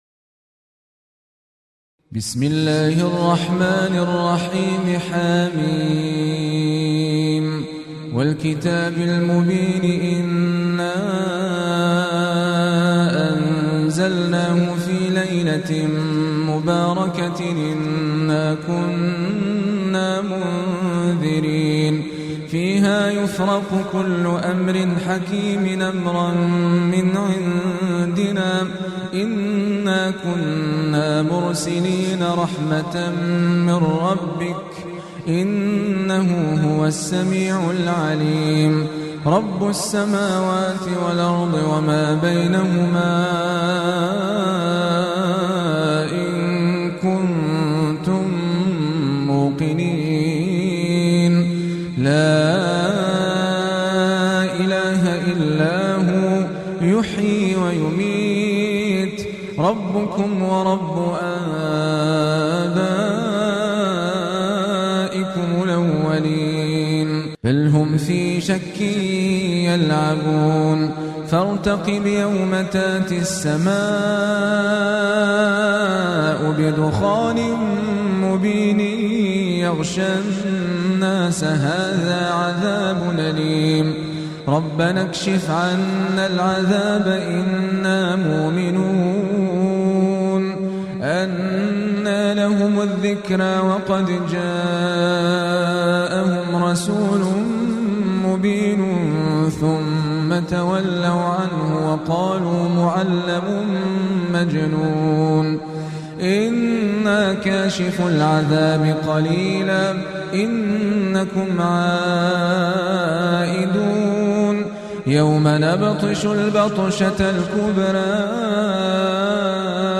القرآن الكريم - تلاوة وقراءة بصوت أفضل القراء